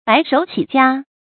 注音：ㄅㄞˊ ㄕㄡˇ ㄑㄧˇ ㄐㄧㄚ
白手起家的讀法